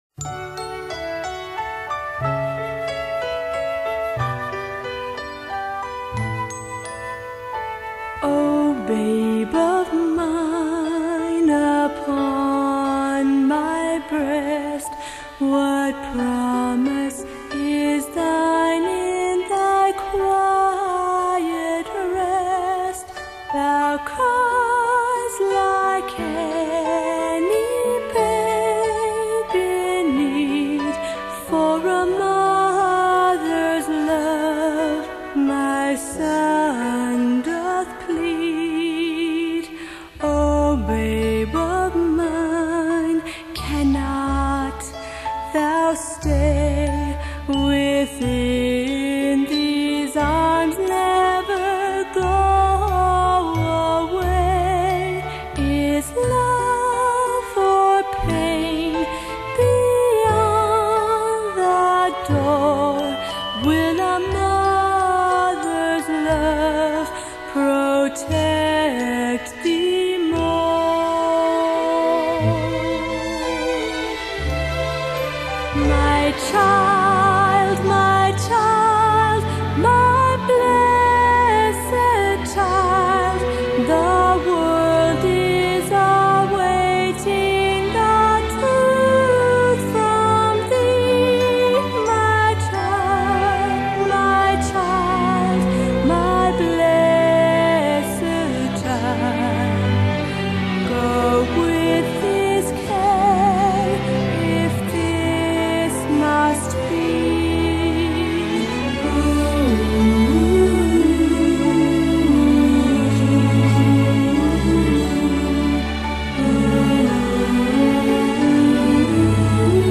Medium Voice/Low Voice
Lullabies